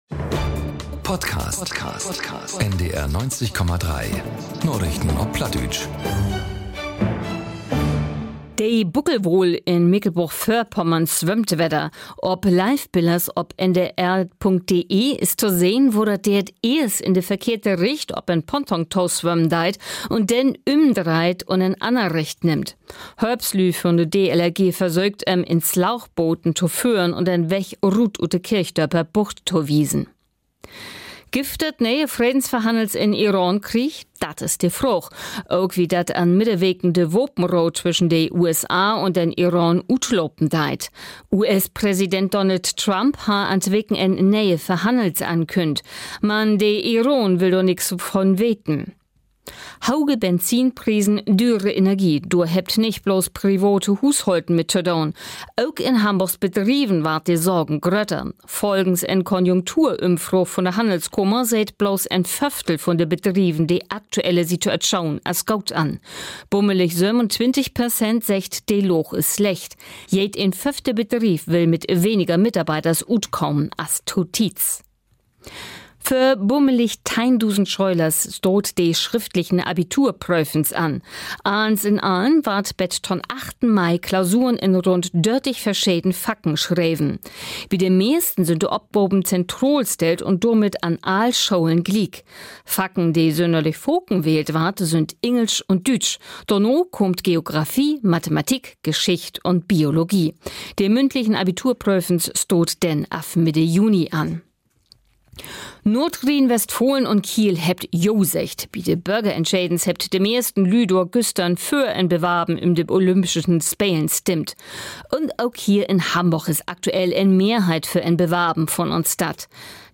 aktuellen Nachrichten auf Plattdeutsch.